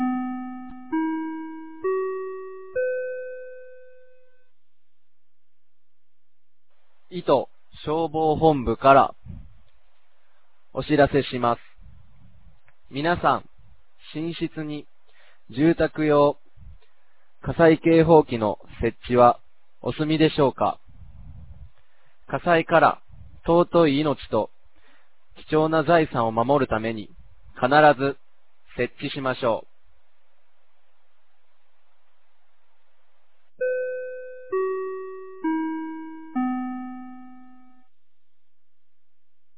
2025年09月22日 10時00分に、九度山町より全地区へ放送がありました。